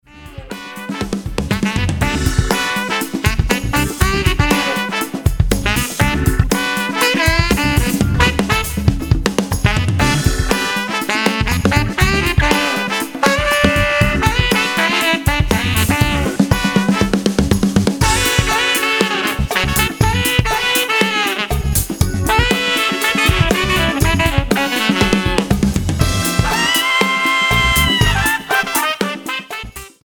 120 BPM
Tenor lead with funky drums, horns and Hammond behind.